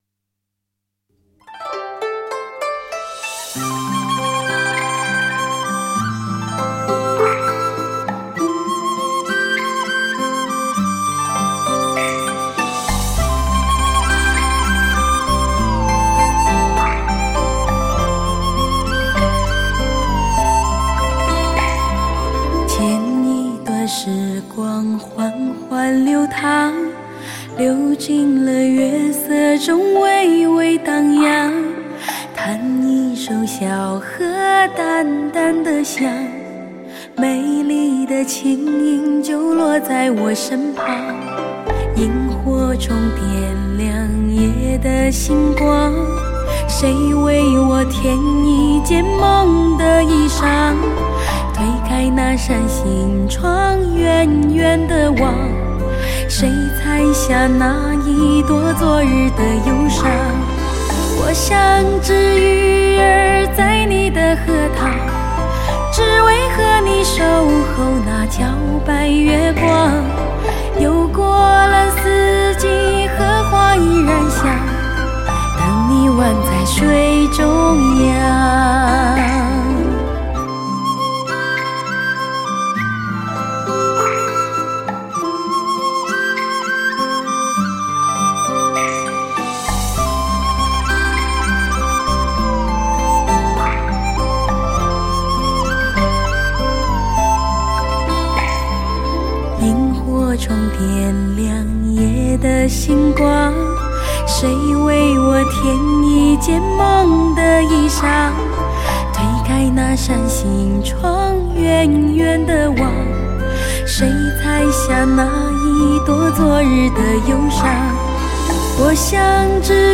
深情、欢愉继续演绎时尚达人的情感，呈现城市生活的画卷.....